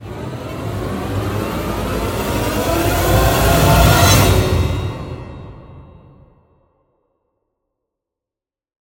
Эффект нарастания "Демоникон"